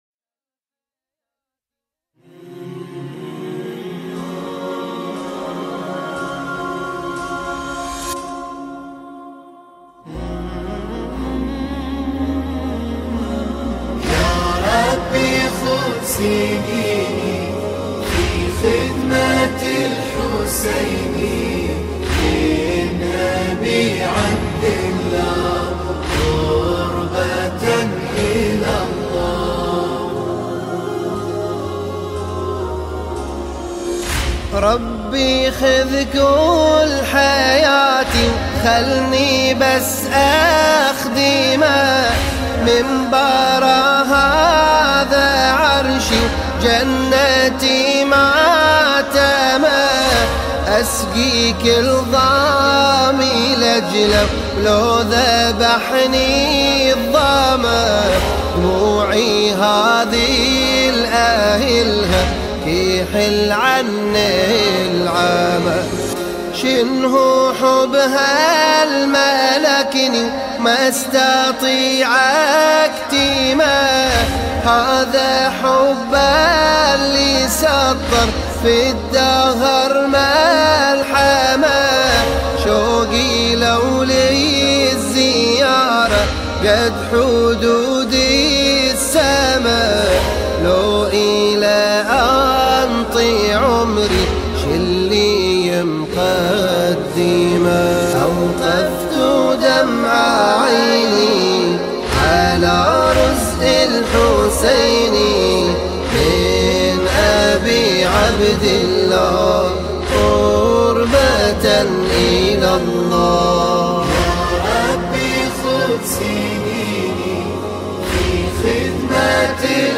دانلود نماهنگ دلنشین عربی